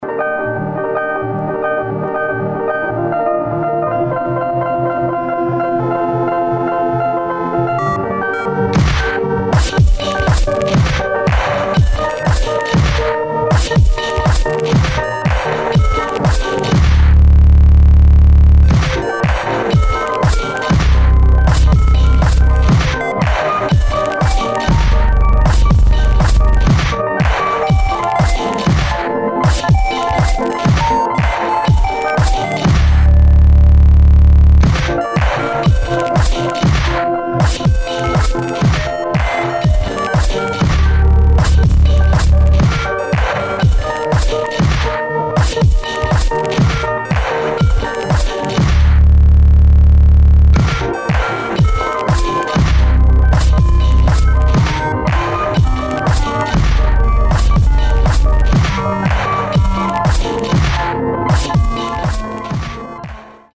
[ DOWNBEAT / ELECTRONIC / DUBSTEP ]